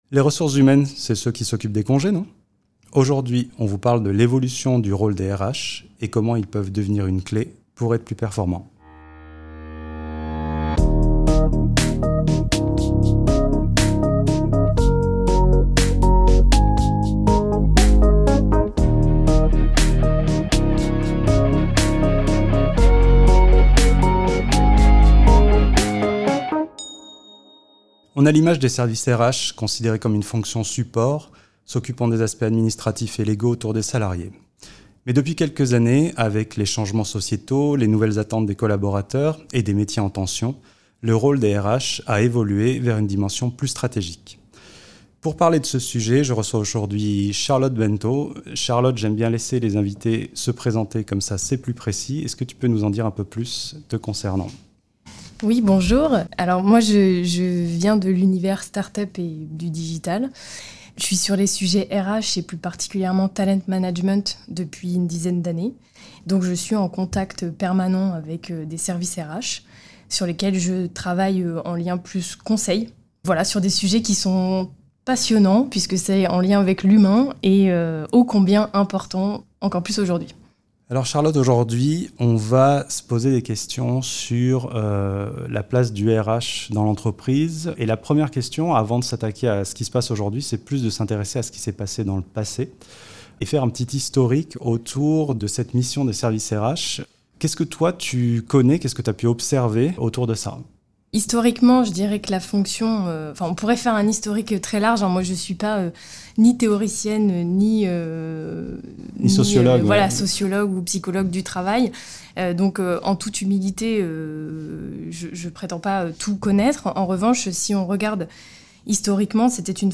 Consultante en stratégie RH pour les entreprises